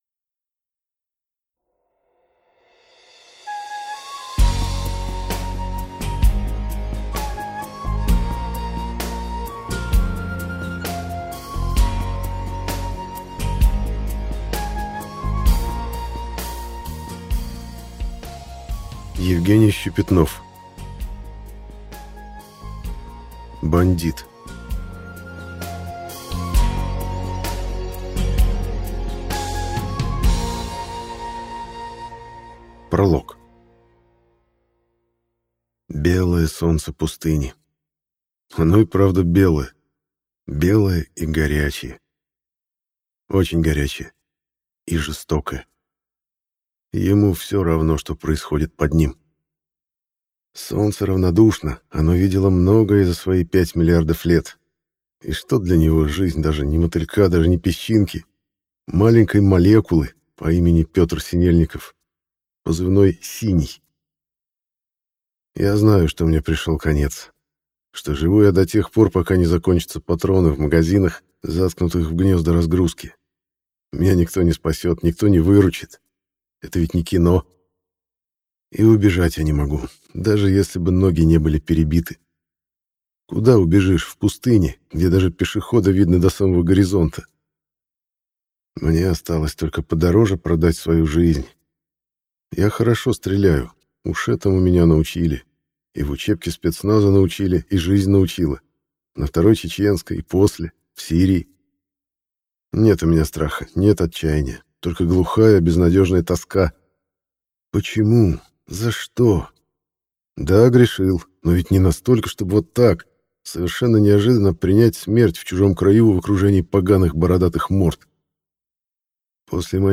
Аудиокнига Бандит | Библиотека аудиокниг